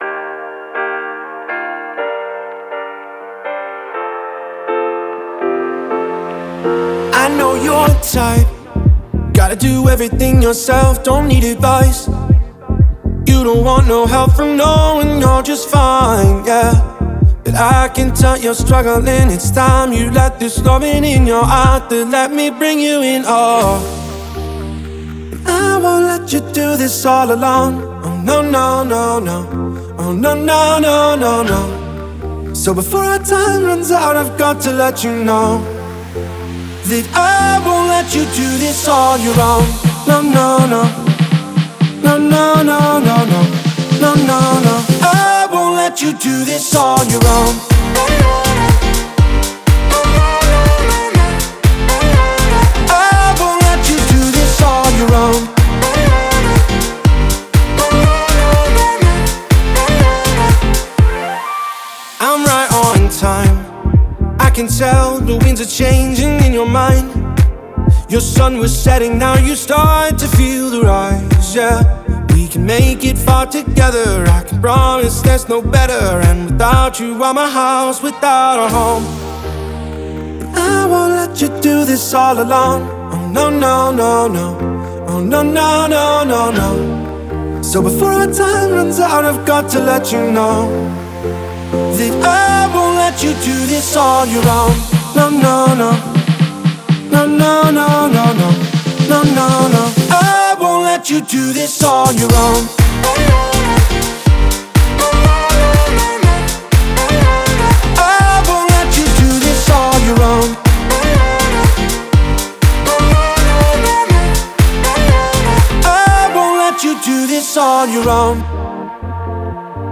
энергичная поп-музыка